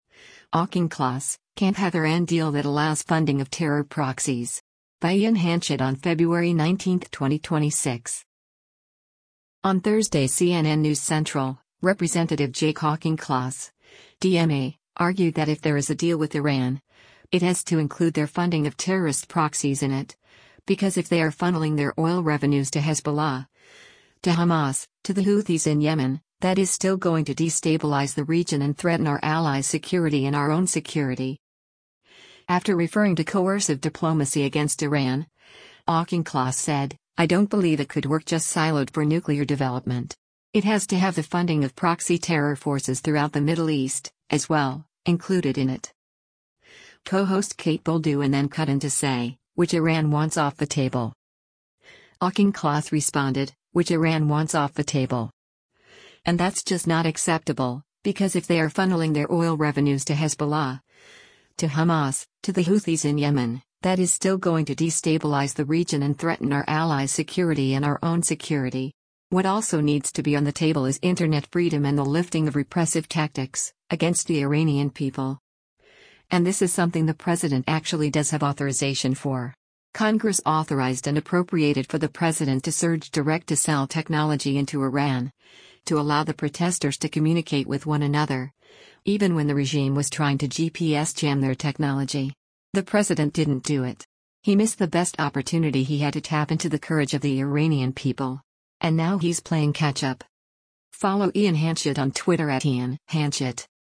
Co-host Kate Bolduan then cut in to say, “Which Iran wants off the table.”